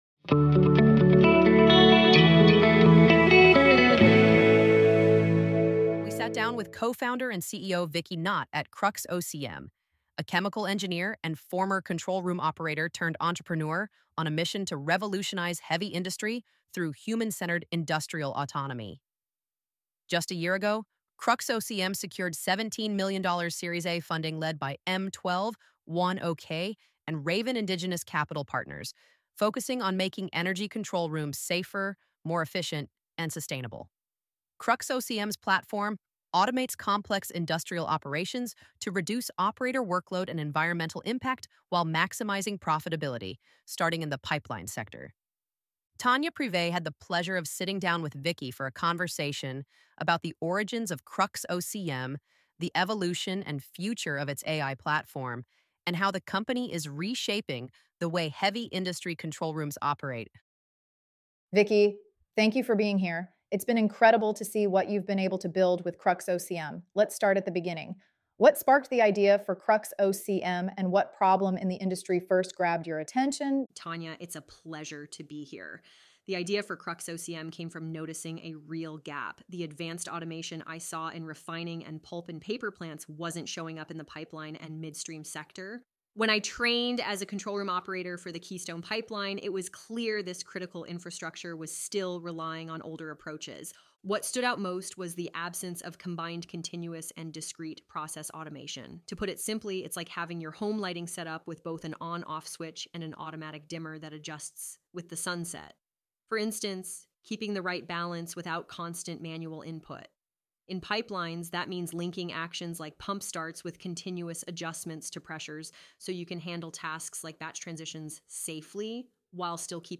Press play to listen to this conversation https